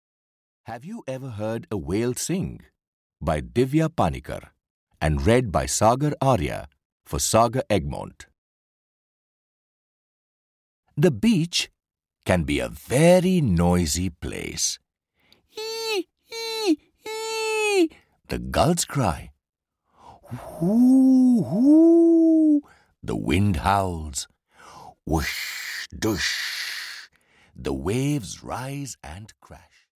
Audio knihaHave you ever Heard a Whale Sing (EN)
Ukázka z knihy